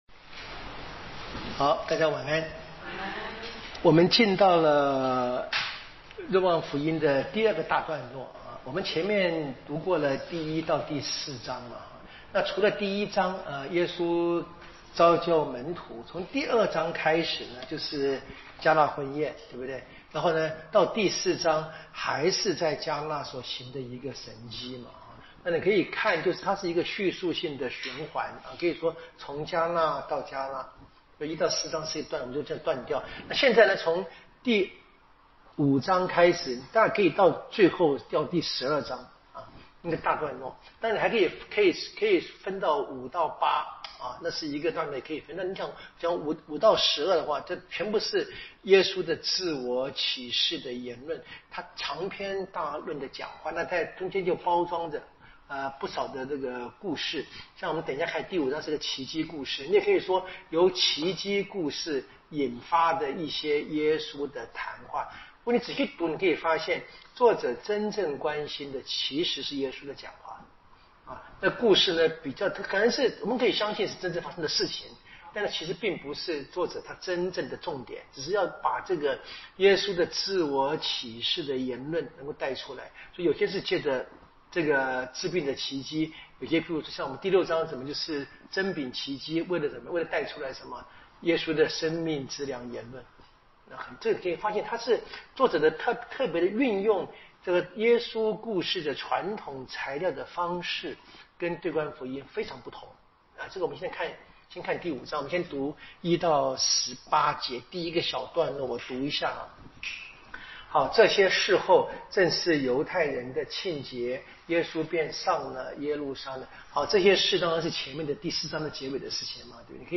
【圣经讲座】《若望福音》